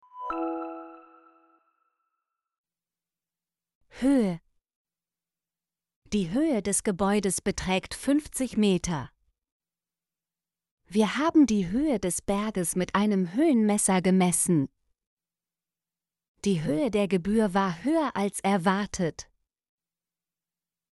höhe - Example Sentences & Pronunciation, German Frequency List